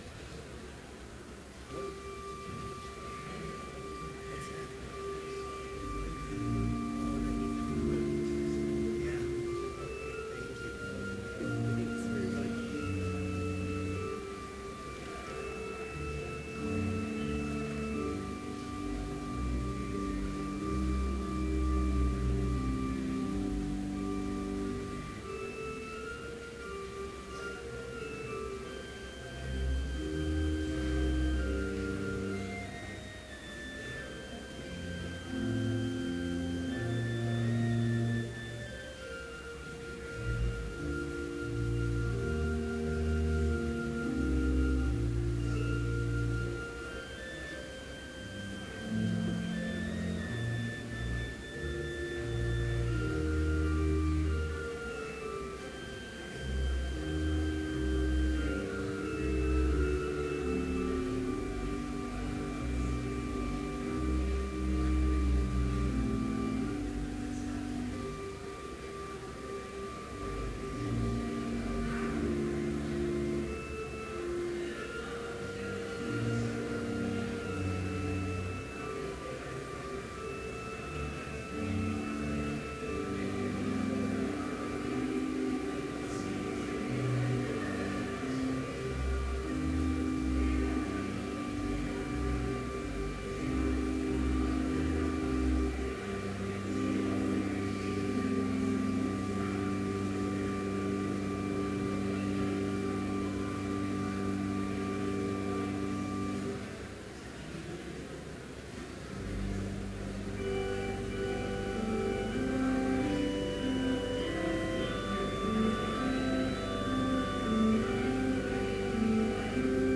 Complete service audio for Chapel - December 5, 2014